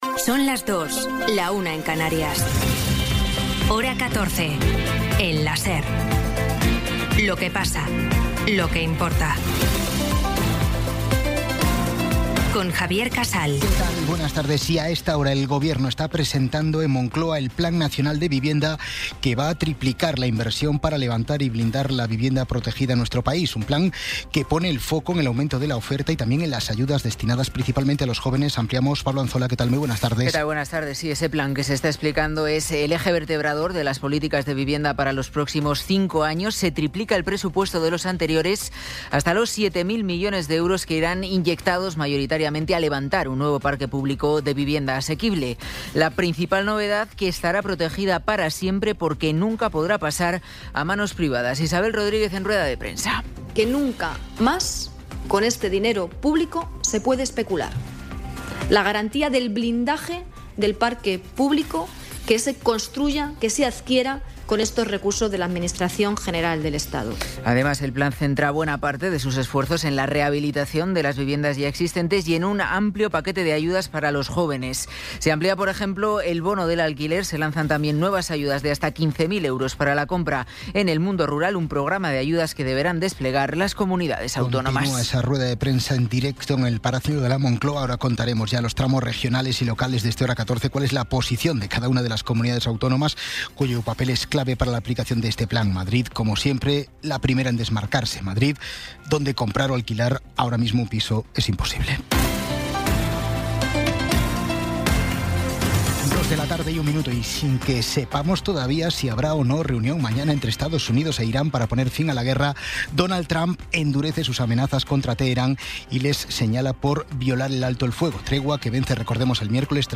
Resumen informativo con las noticias más destacadas del 21 de abril de 2026 a las dos de la tarde.